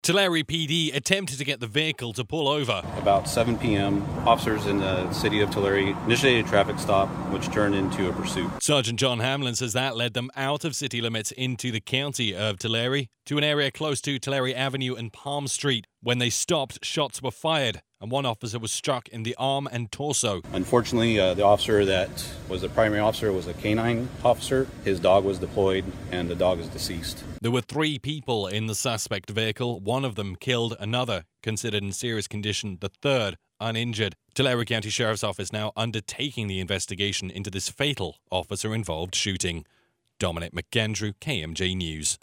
DM-TULARE-K9-OIS2.mp3